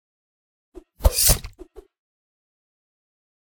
sword-001-01.ogg